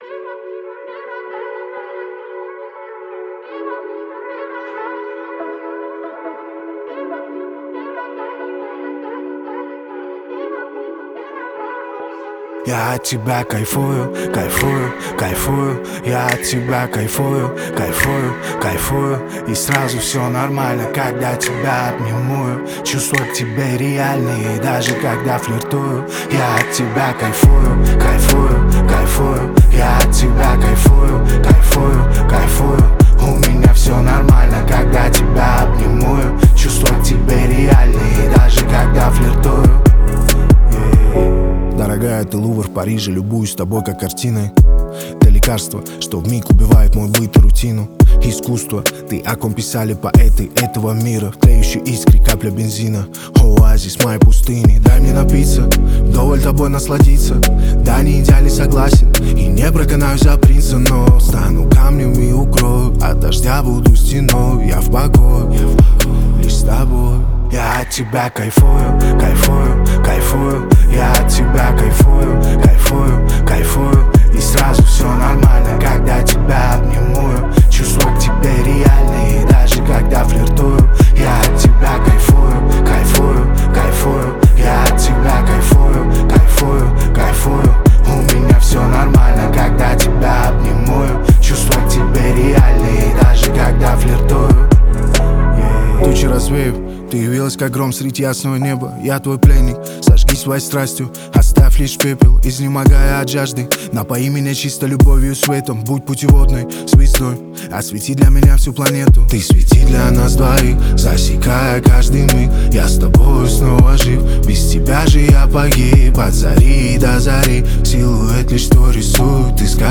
Хаус музыка